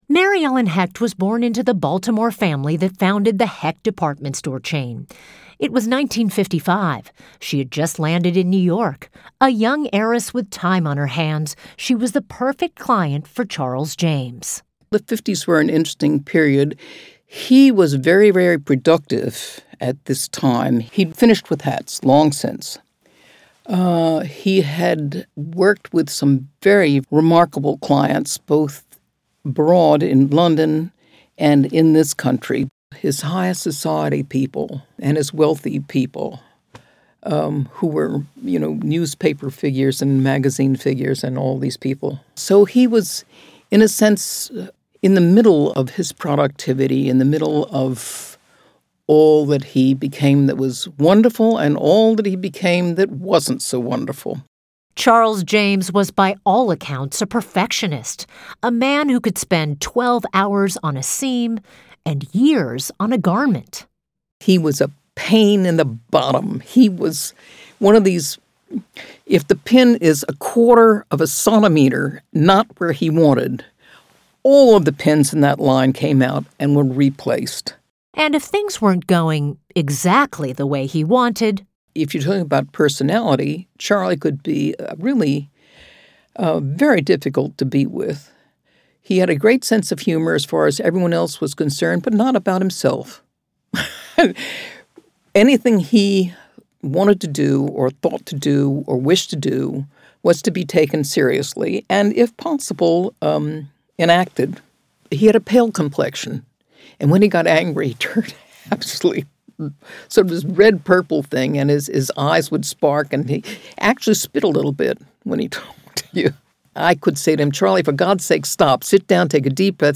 Recalling Charles James: Audio Interviews
The interviews are chronologically ordered according to the date each subject knew Charles James.